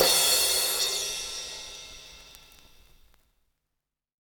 CymST_70s_1_MPC60V.wav